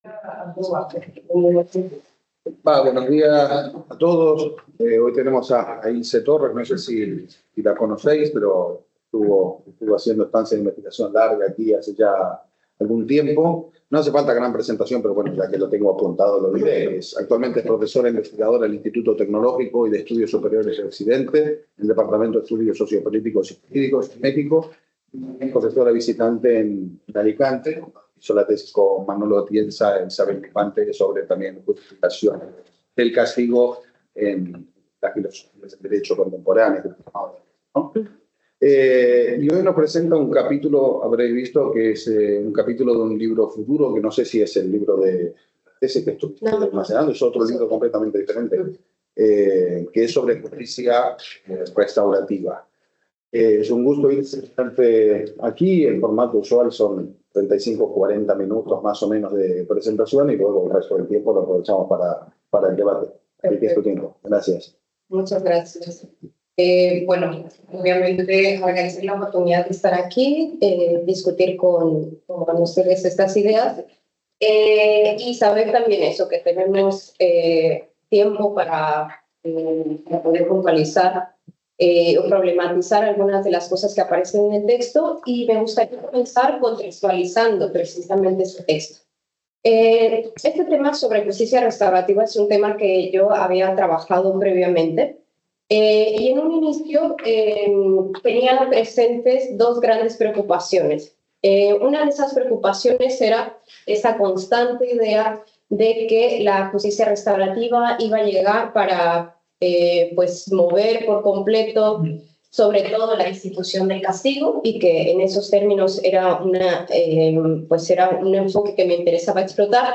presents her paper on punishment and restorative justice. Among others, he tells us about the concept of liberal criminal law  Aquest document està subjecte a una llicència Creative Commons: Reconeixement – No comercial – Compartir igual (by-nc-sa) Mostra el registre complet de l'element